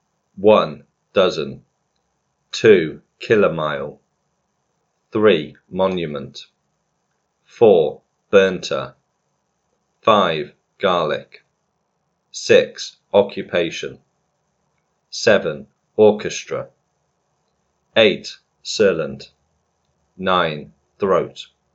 As you heard, in the audio type questions you hear 9 words. 3 of these are not real words.
Be aware that these words may therefore sound different, perhaps with a stronger stress than usual.